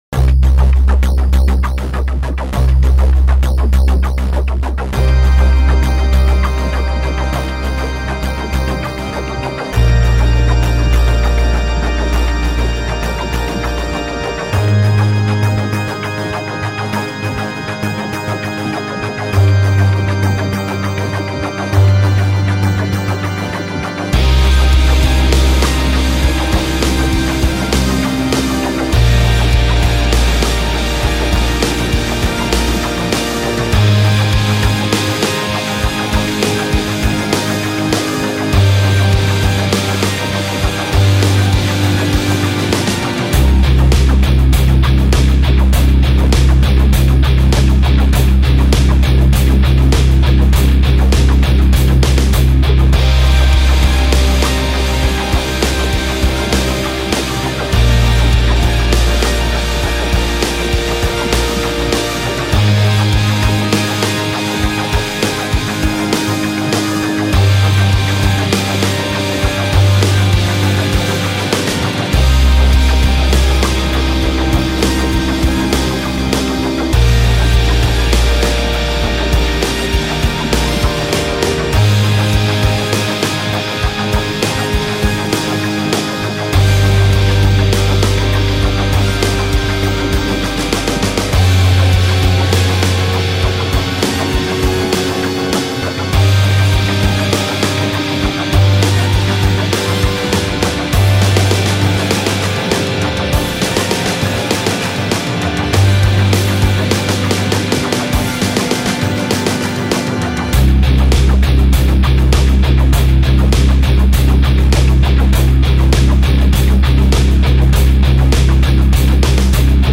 フリーBGM ダンジョン